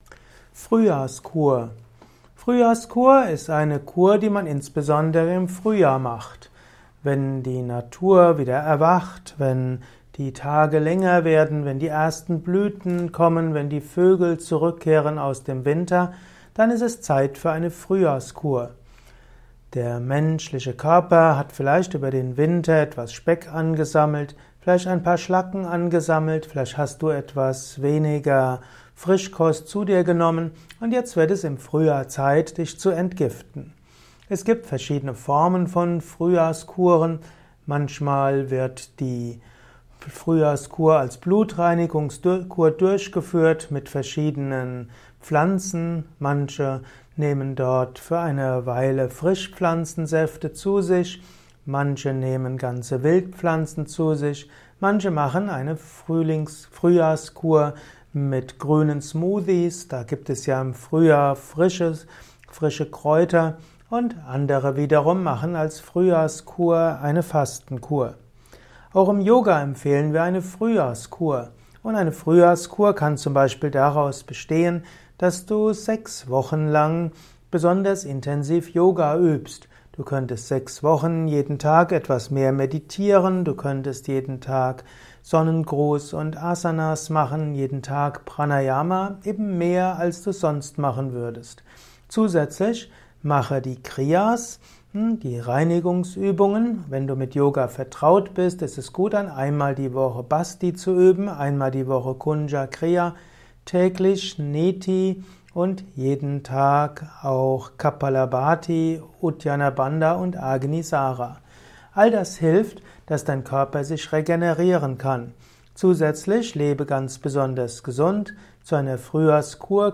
Ein Kurzvortrag über Frühjahrskur